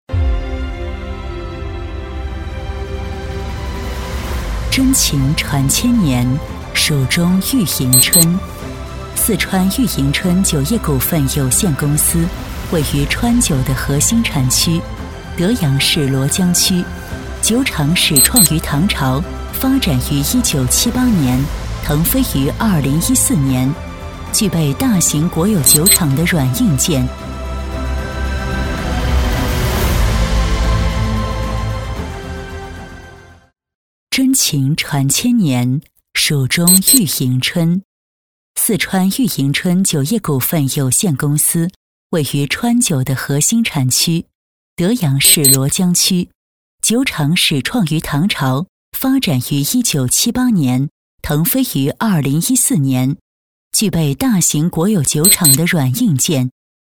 v3-专题 - 四川酒业 大气浑厚
v3-专题---四川酒业-大气浑厚.mp3